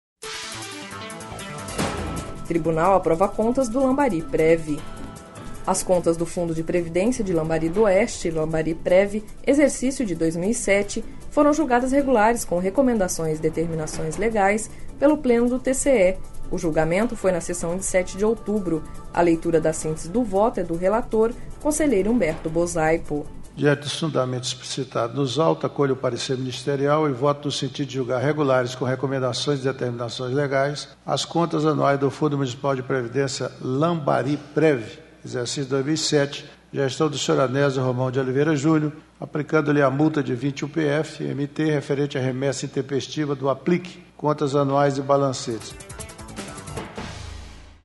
As contas do Fundo de Previdência de Lambari D’Oeste - LAMBARIPREVI, exercício de 2007, foram julgadas regulares com recomendações e determinações legais pelo Pleno do TCE./ O julgamento foi na sessão de 07 de outubro./ A leitura da síntese do voto é do relator conselheiro Humberto Bosaipo.//
Sonora: Humberto Bosaipo – conselheiro do TCE-MT